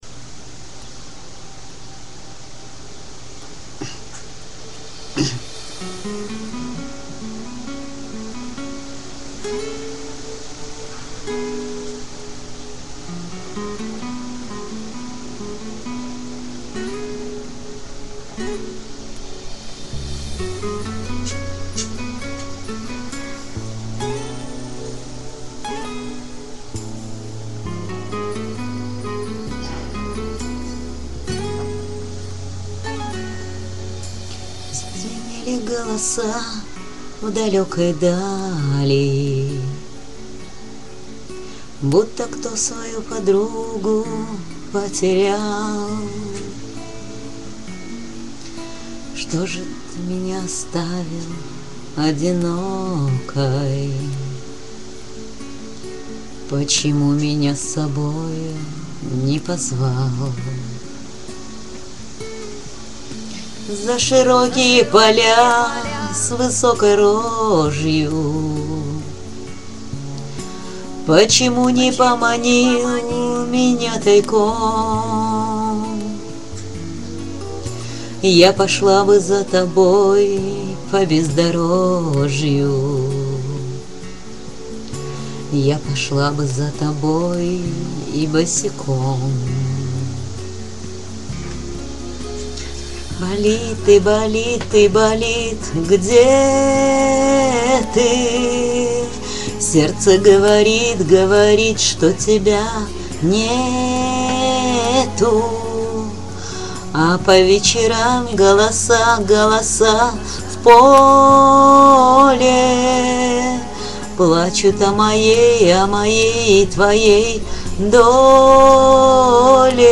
Кавер-версия